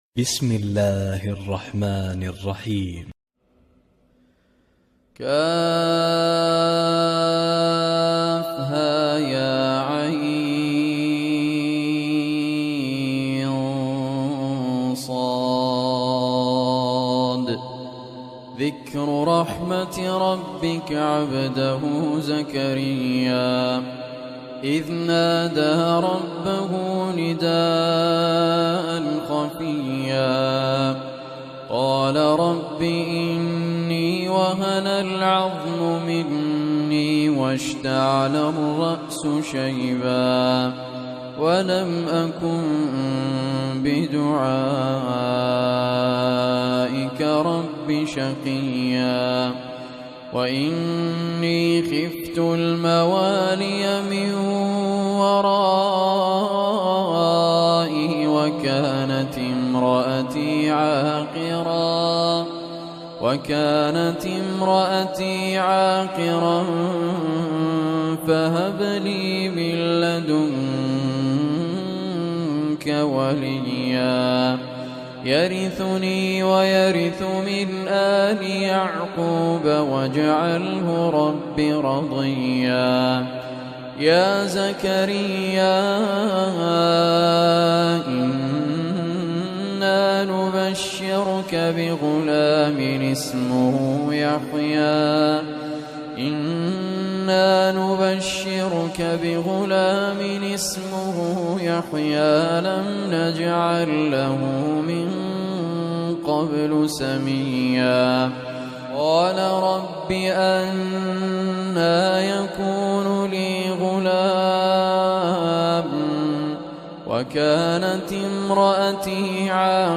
Surah Maryam Mp3 Recitation by Raad Al Kurdi
Surah Maryam is 19th chapter of Holy Quran. Listen or play online mp3 tilawat / recitation in the beautiful voice of Raad Muhammad al Kurdi.